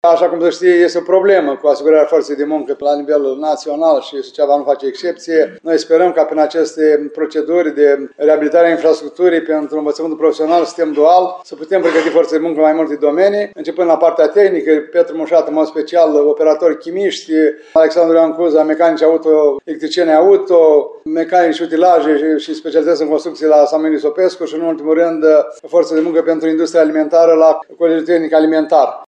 Primarul ION LUNGU a declarat astăzi că aceste proiecte sunt deosebit de importante, deoarece “o mare problemă cu care se confruntă agenții economici este legată de lipsa forței de muncă calificată”.